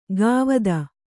♪ gāvada